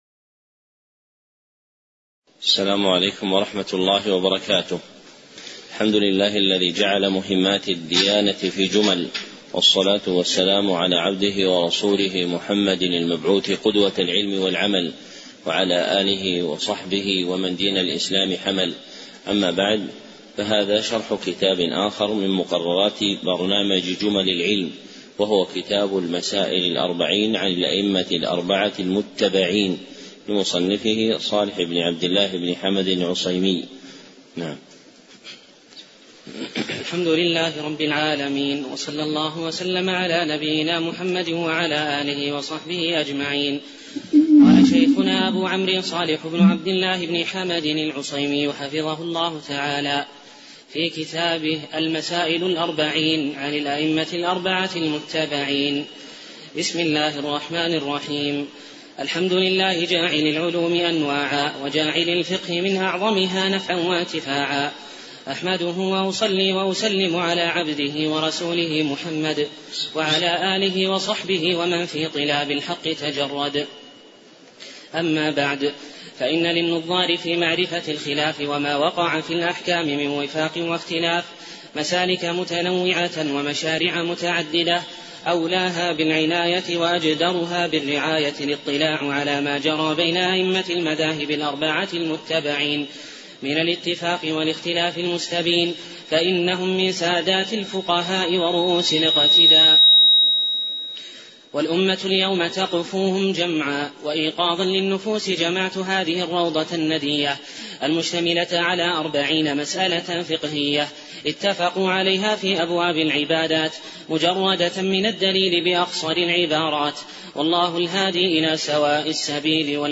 شرح المسائل الأربعين عن الأئمة الأربعة المتبعين [ برنامج جمل العلم بالمدينة 1432هـ